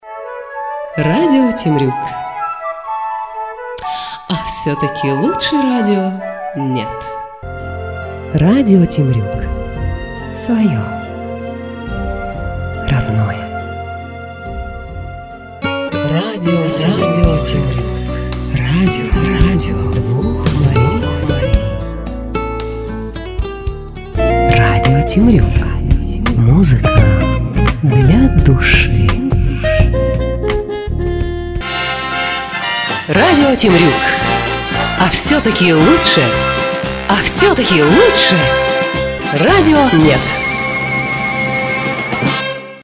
Джинглы Категория: Аудио/видео монтаж